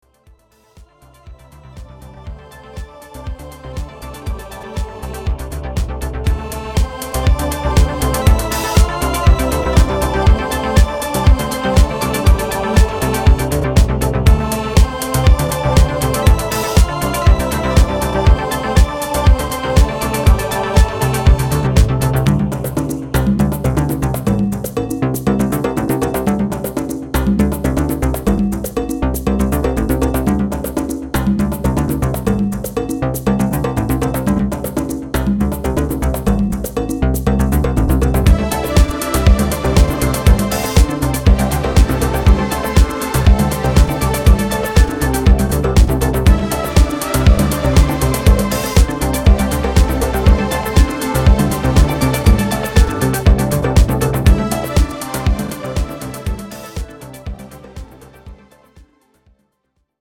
VHS grained, voodoo possessed electronic disco